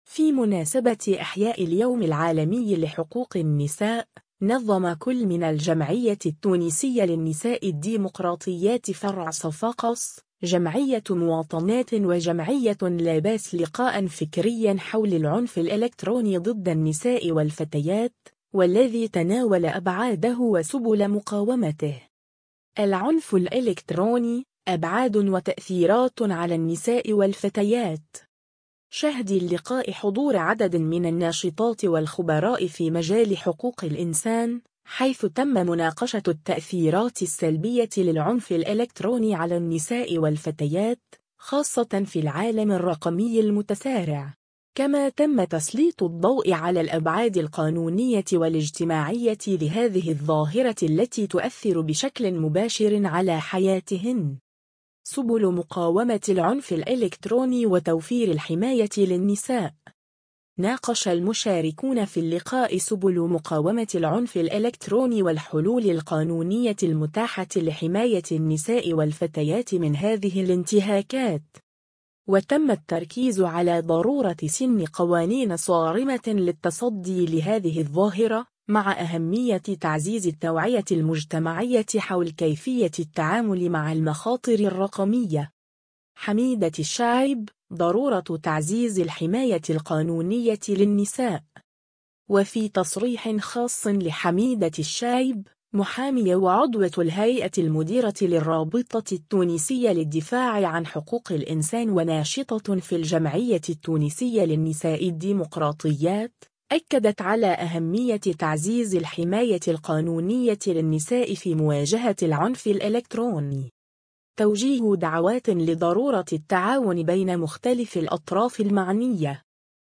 في مناسبة إحياء اليوم العالمي لحقوق النساء، نظم كل من الجمعية التونسية للنساء الديمقراطيات فرع صفاقس، جمعية مواطنات و جمعية لاباس لقاءً فكريًا حول العنف الإلكتروني ضد النساء والفتيات، والذي تناول أبعاده وسبل مقاومته.